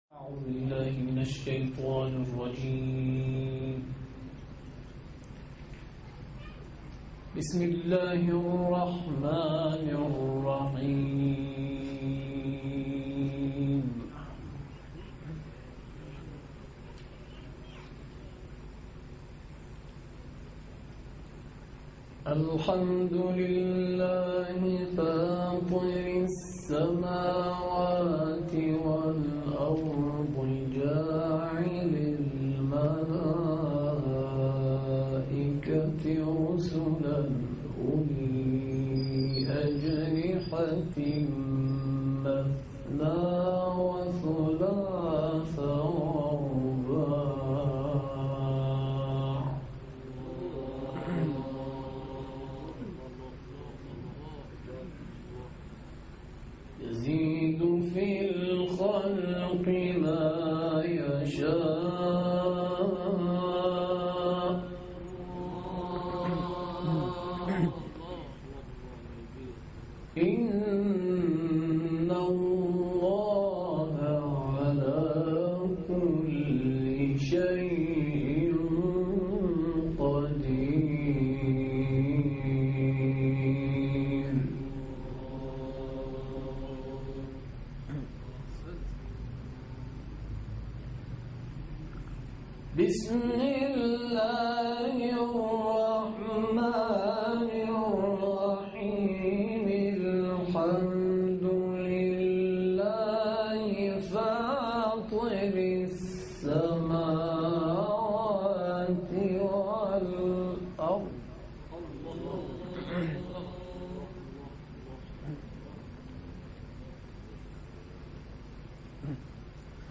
این تلاوت 15 دقیقه ای در سال 1390 در قم اجرا شده است.